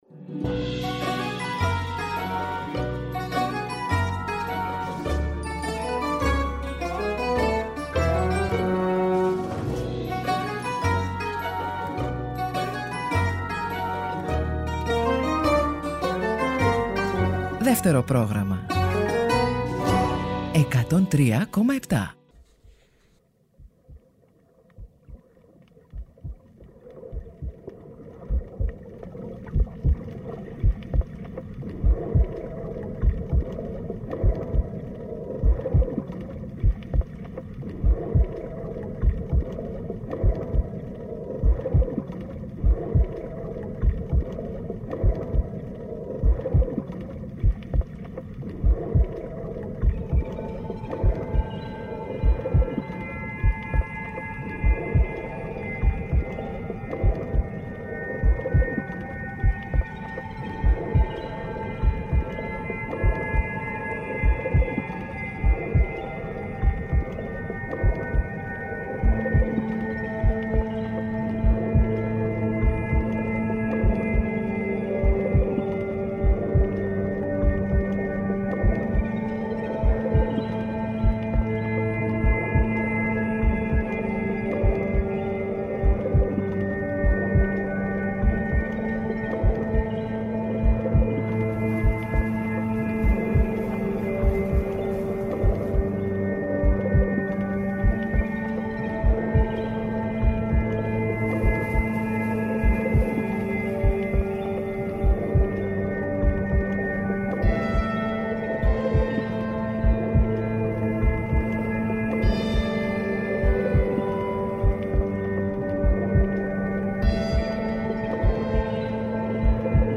Greek